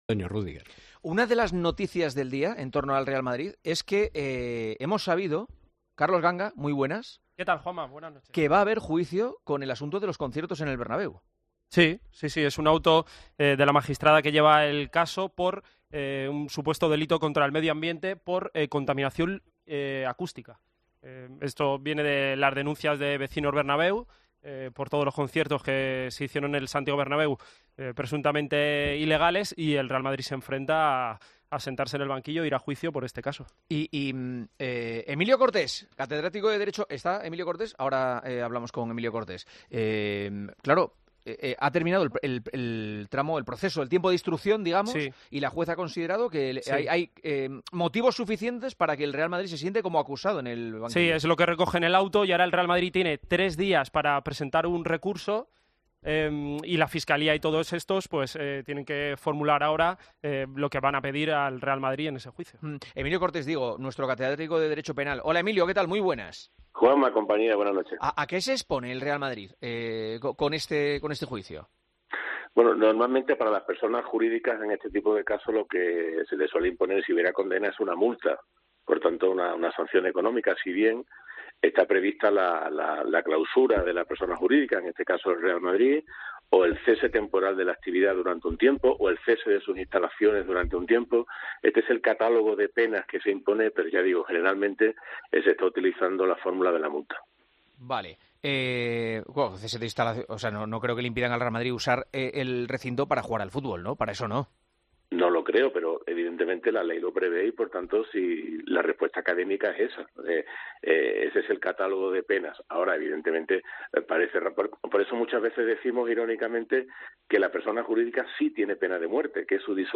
La discusión sobre la rapidez de la justicia en este caso frente a otros ha provocado un cruce de acusaciones entre los tertulianos, con comentarios sobre un supuesto "victimismo" y una "confabulación" contra el Real Madrid.